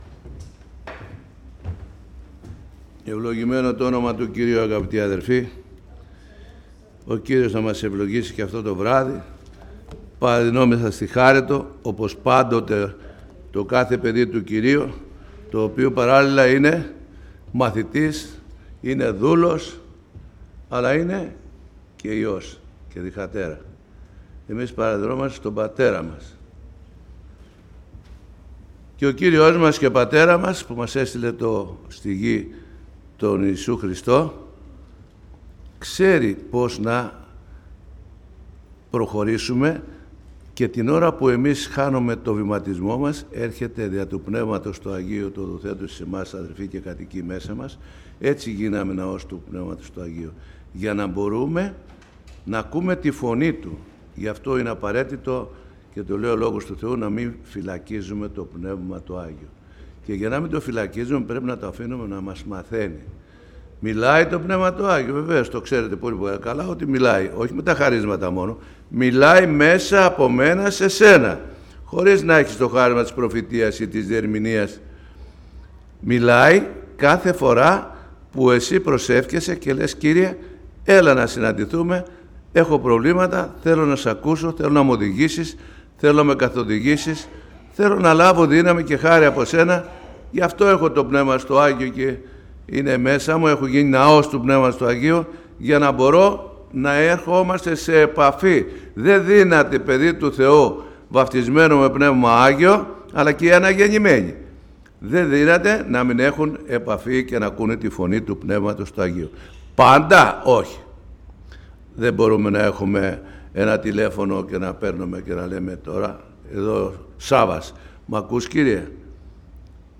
Κήρυγμα Ευαγγελίου